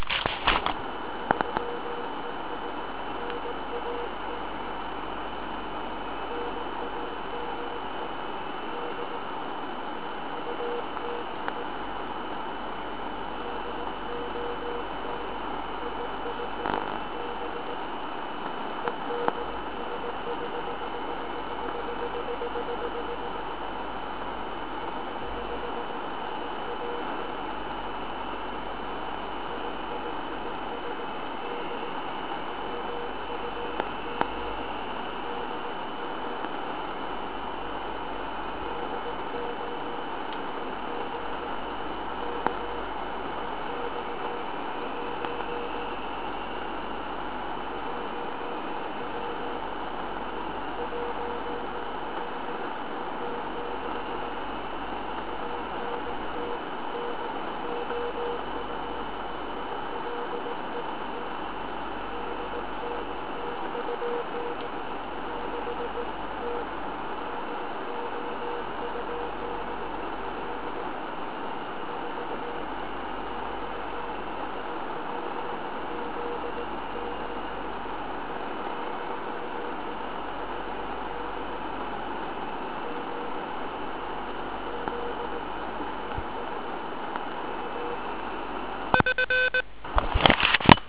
A kdy� sly��te ty n�dhern� slab� sign�lky, p�ipad�m si v sedm�m nebi.